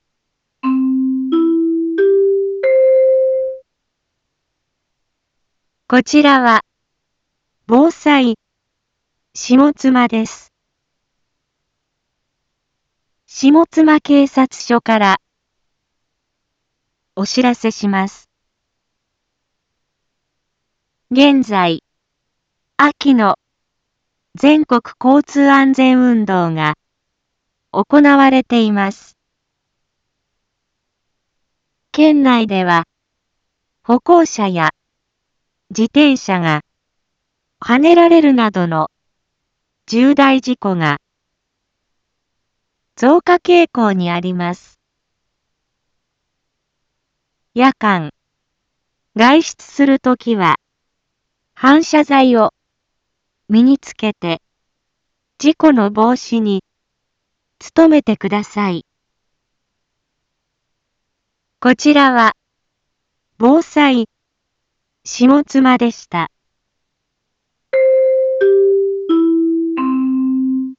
一般放送情報
Back Home 一般放送情報 音声放送 再生 一般放送情報 登録日時：2021-09-30 17:31:14 タイトル：秋の全国交通安全運動 インフォメーション：こちらは、防災下妻です。